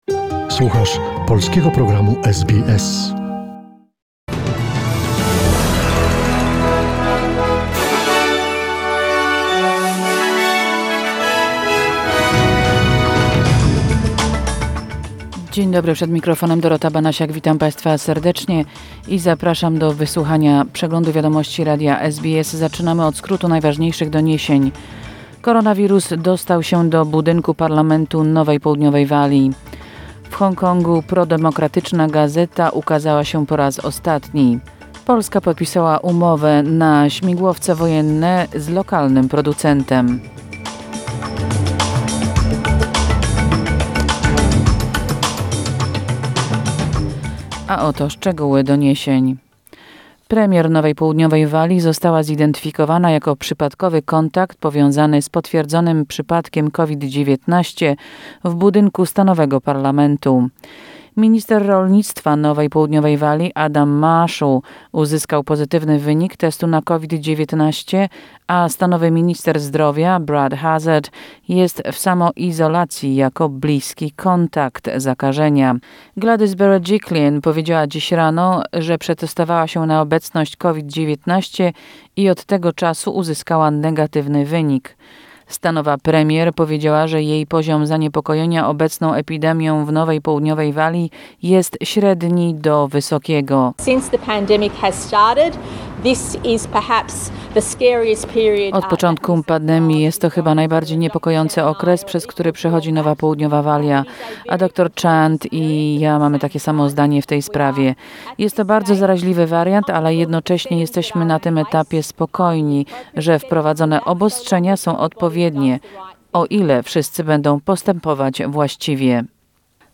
SBS News Flash in Polish, 24 June 2021